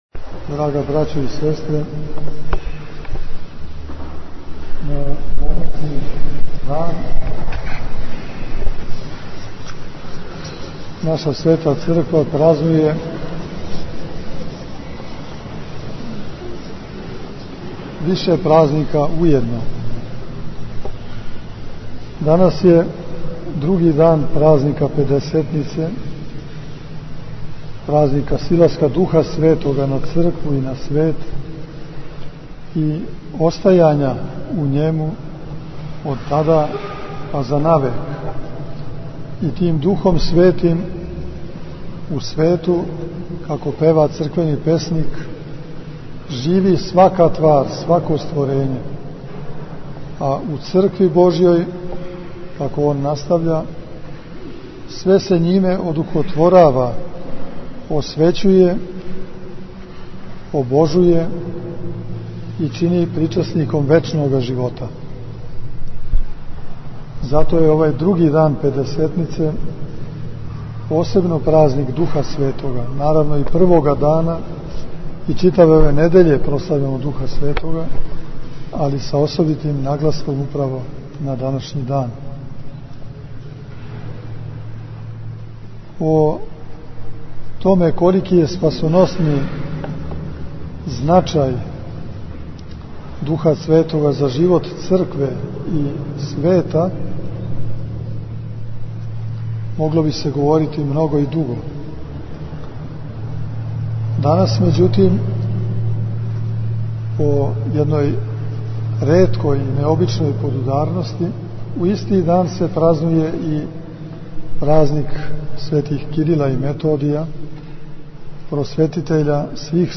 Епископ Иринеј началствовао литургијским слављем на Телепу у Новом Саду
На Духовски понедељак, 24. маја текуће године, Његово Преосвештенство Епископ бачки Господин др Иринеј началствовао је евхаристијским сабрањем у храму Светих Кирила и Методија на Телепу у Новом Саду.
Беседа Епископа Иринеја